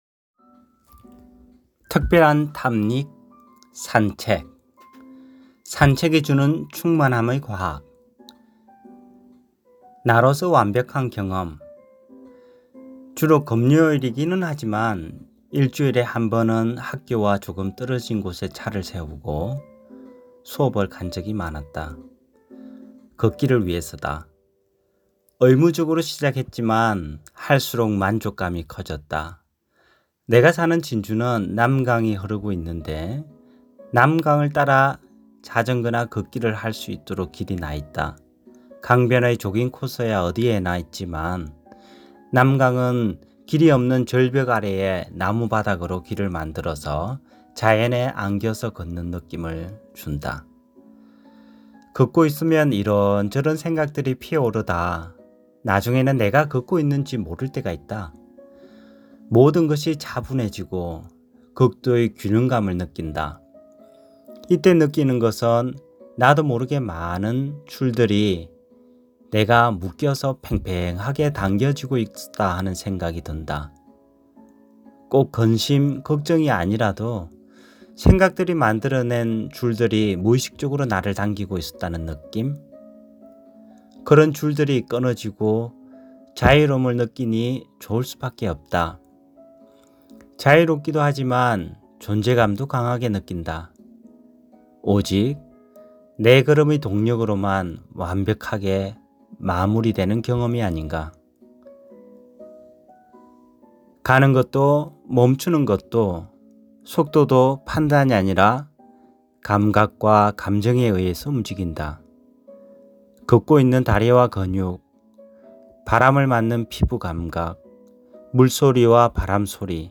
긴 글이 부담스러운 사람들에게, 투박하게 글 읽어주는 사운드 파일입니다.